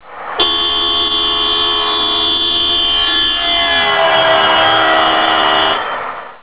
Sounds Click here to hear the doppler effect. (Volvo blowing horn at 30 miles per hour.)
30_MPH_doppler.au